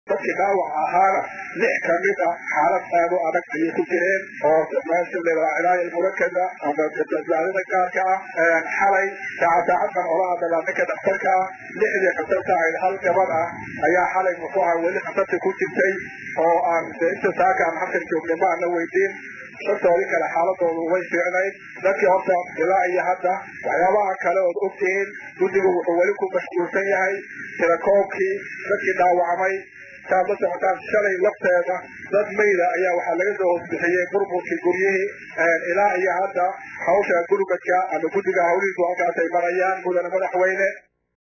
Banaabax loogu magac daray maalinta cadhada ayaa lagu qabtay Garoonka burburay ee Koonis Stadium ee magaalada Muqdisho.